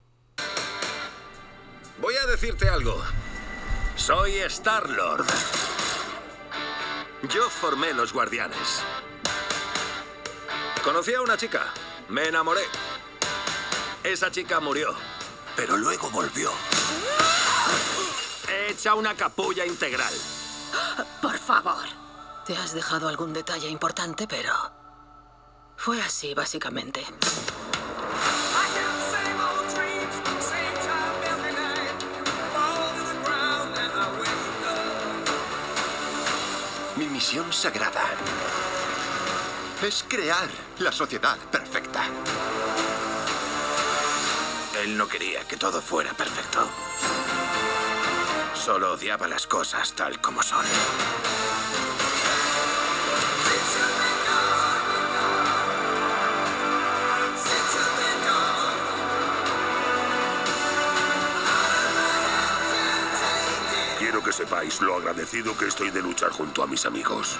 El sistema de sonido equipado consiste en 2 altavoces de 1W cada uno, con tecnología Smart Amp y compatibilidad con Dolby Atmos.
Sin embargo hacen un muy buen papel en cuanto a claridad y detalle de sonido, con agudos y medios bien controlados sin distorsión.